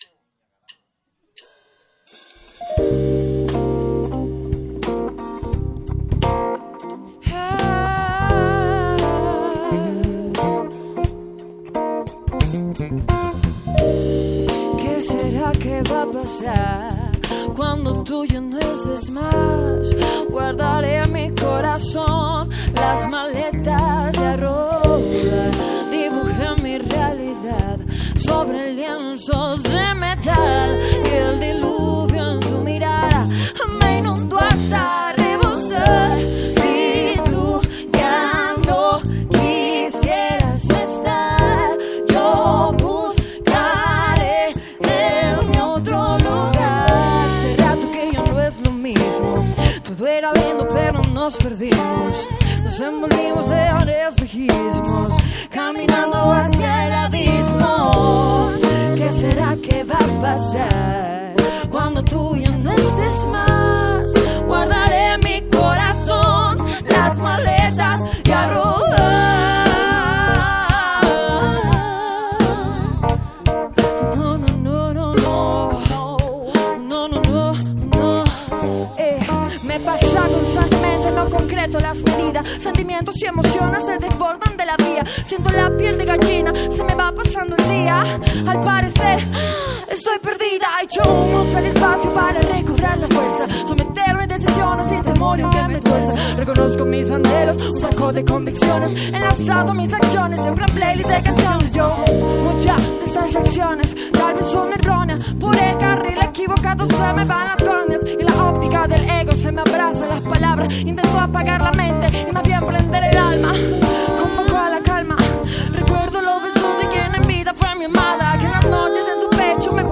cantante ecuatoriana emergente